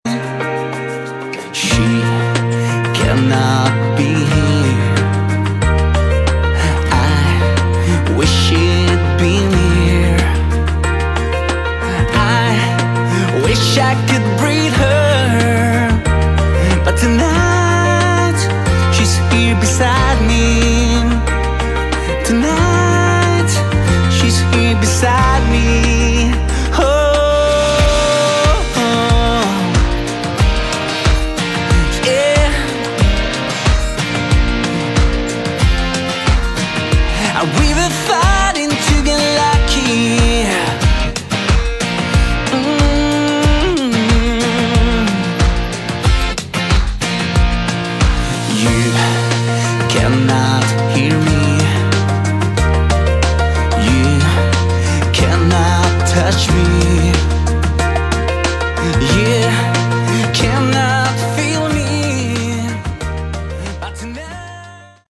subtle Hard Rock/AOR album
genuine and original Melodic hard rock with AOR flavour